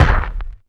SQUISH.wav